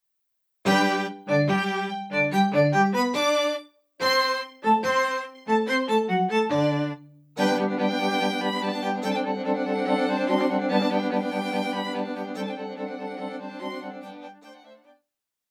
古典
中提琴,大提琴,小提琴
室內樂曲
四重奏
有主奏
有節拍器